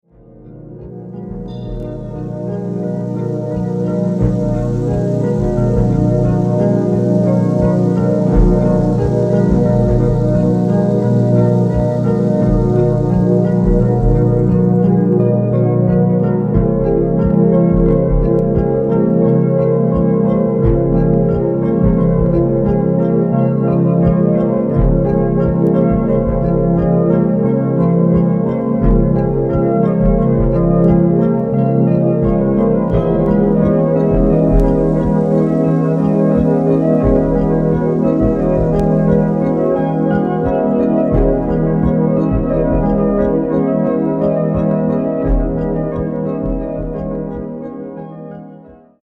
キーワード：ミニマル